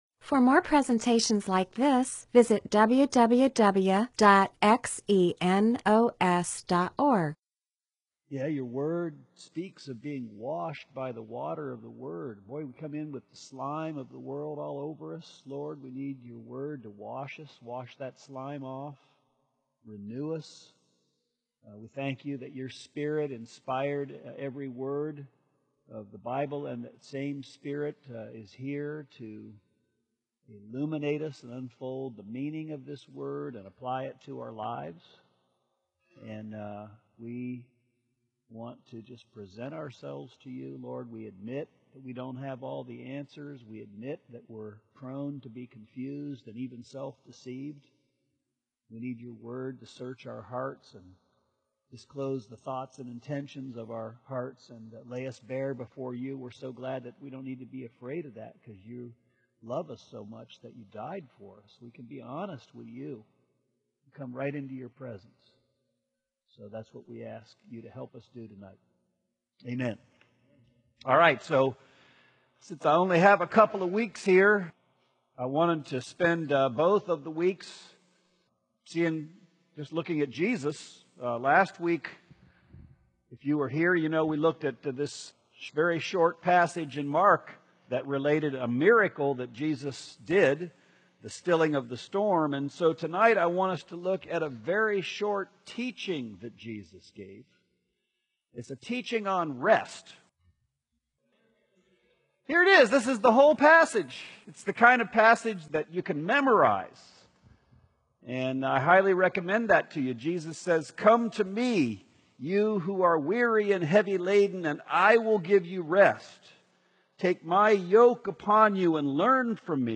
MP4/M4A audio recording of a Bible teaching/sermon/presentation about Matthew 11:28-30.